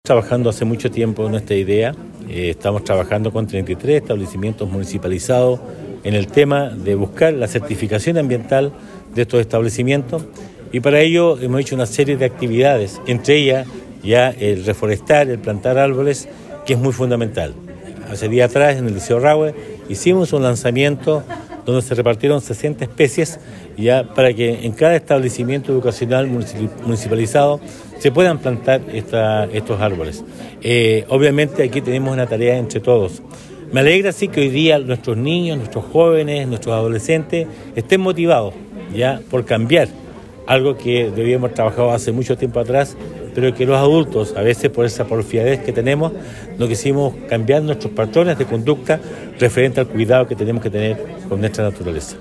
Del mismo modo el Alcalde Emeterio Carrillo indicó que se está trabajando en la educación medioambiental en todos los establecimientos de la comuna, como por ejemplo la reforestación de los espacios verdes.